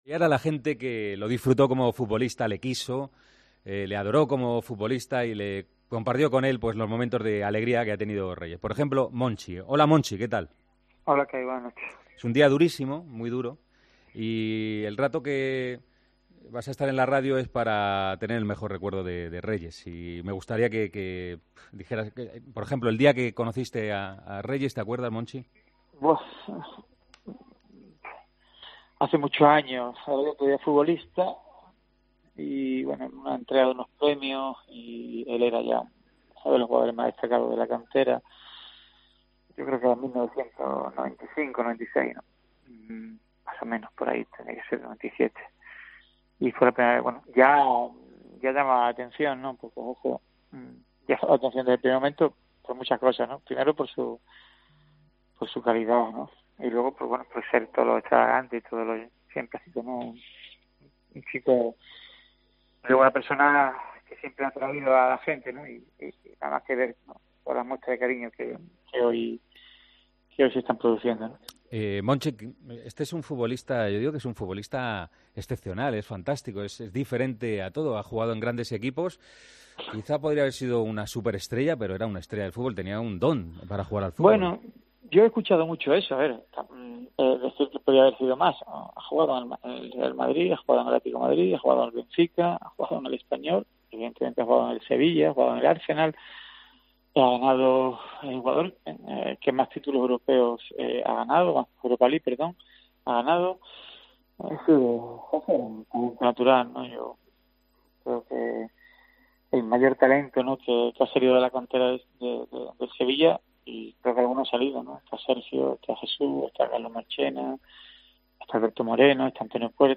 Hablamos con el director deportivo del Sevilla, Monchi